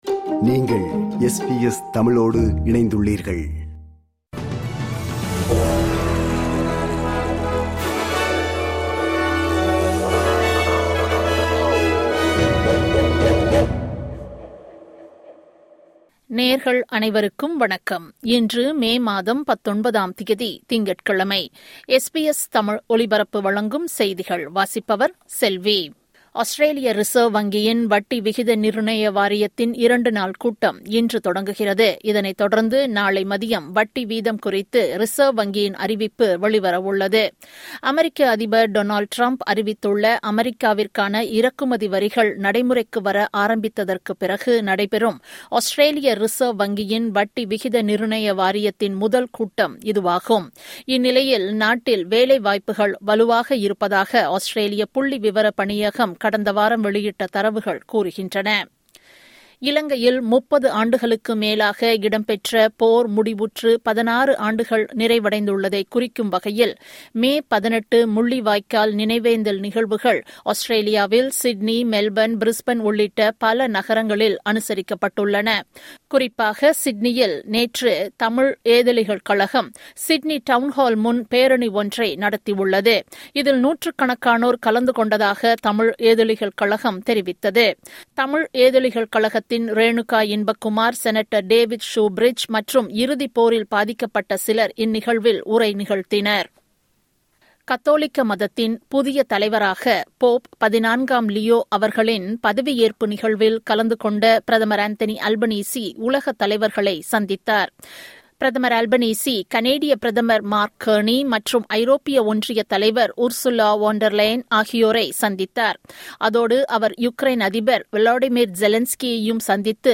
SBS தமிழ் ஒலிபரப்பின் இன்றைய (திங்கட்கிழமை 19/05/2025) செய்திகள்.